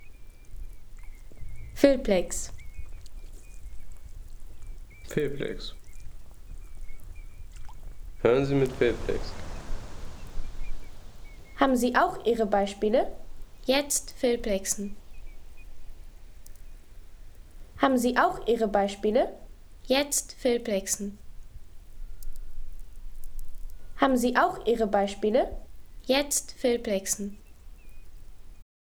Erholungsgenuss für alle, wie z.B. diese Aufnahme: Vogelschwärme im Wattenmeer
Vogelschwärme im Wattenmeer